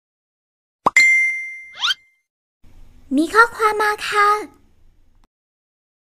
เสียงแจ้งเตือนมีข้อความมาค่ะ
หมวดหมู่: เสียงเรียกเข้า